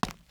Footsteps / Concrete
Concrete-01.wav